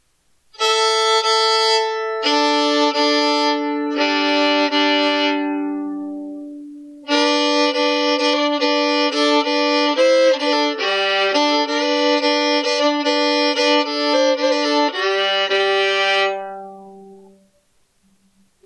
New Handmade Violin / Fiddle Outfit with case & bow - $425.00
I would classify this one as medium loud to loud in volume, with an all around moderate tone quality. Nice depth. Nice bass end, but not bassy overall.